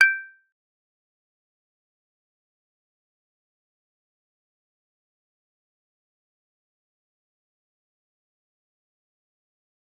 G_Kalimba-G6-f.wav